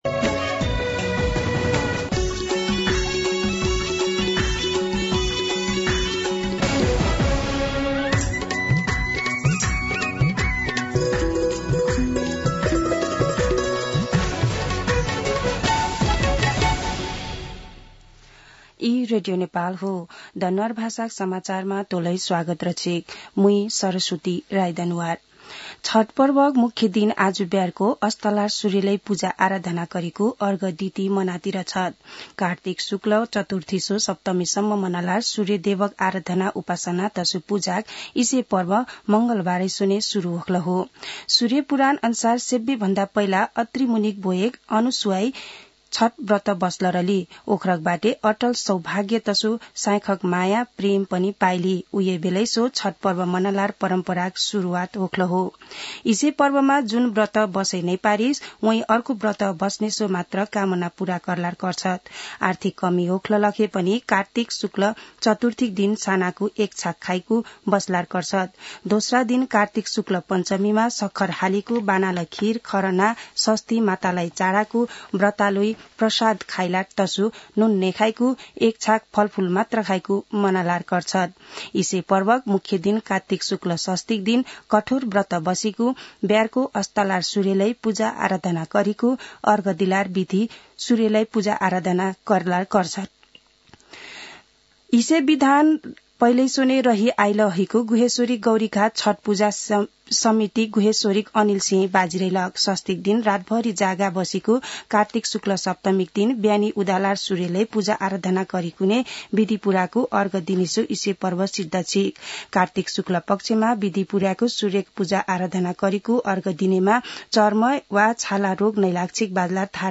दनुवार भाषामा समाचार : २३ कार्तिक , २०८१